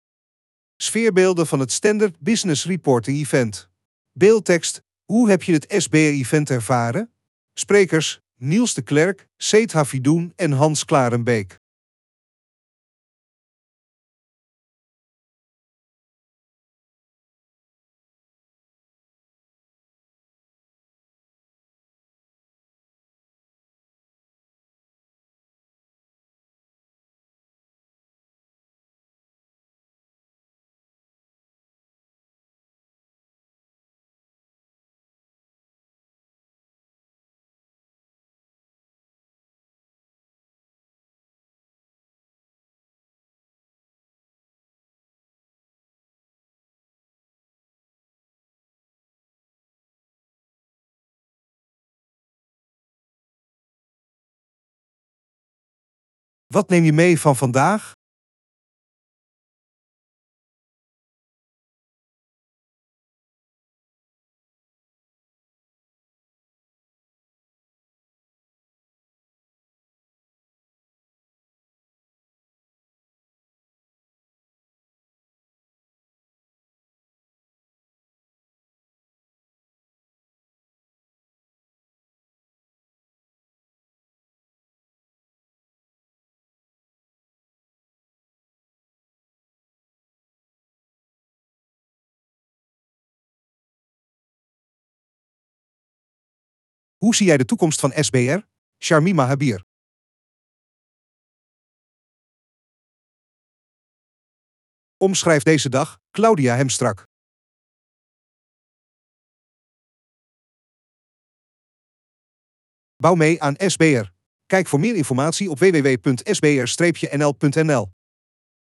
Het NBC Congrescentrum in Nieuwegein vormde het decor van het SBR Event.